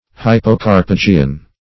Search Result for " hypocarpogean" : The Collaborative International Dictionary of English v.0.48: Hypocarpogean \Hy`po*car`po*ge"an\, a. [Pref. hypo- + Gr.